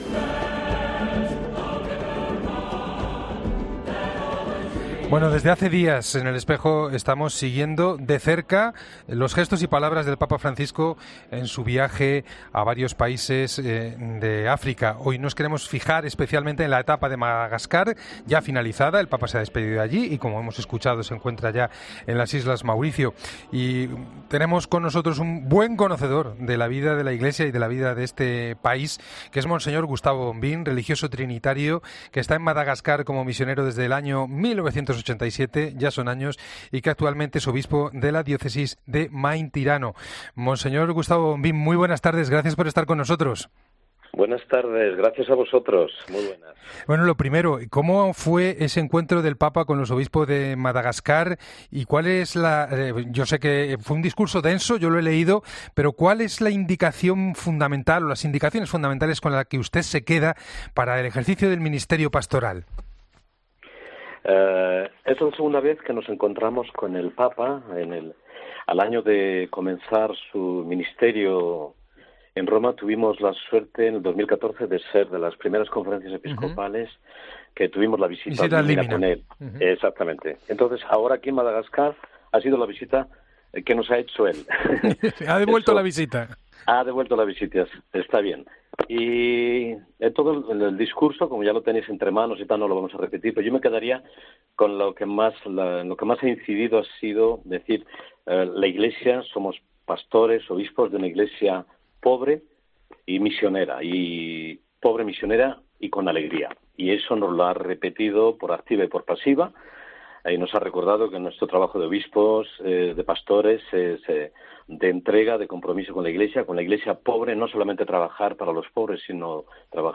AUDIO: El español, obispo de la diócesis de Maintirano, nos habla de la esperanza que el Papa le lleva al pueblo malgache en este viaje